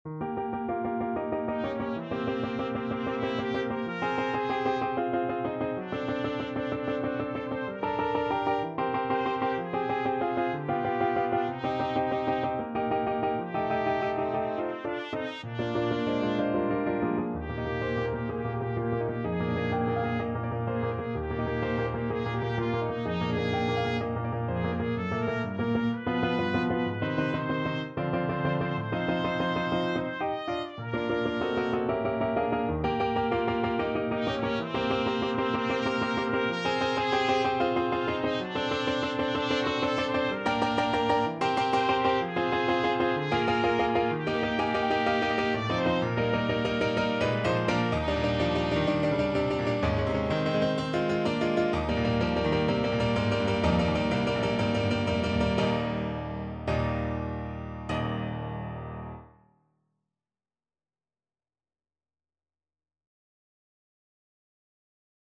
Classical
Trumpet version
Score Key: Eb major (Sounding Pitch)
Time Signature: 2/4
Ziemlich rasch, leidenschaftlich